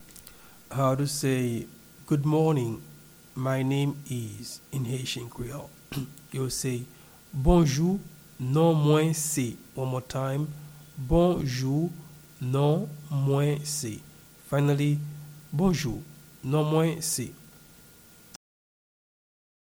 Pronunciation and Transcript:
Good-morning-my-name-is-in-Haitian-Creole-Bonjou-non-mwen-se-pronunciation.mp3